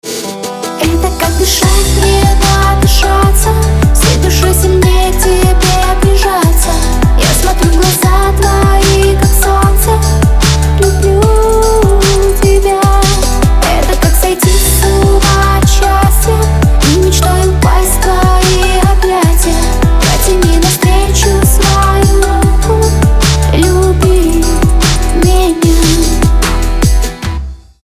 поп
женский вокал
dance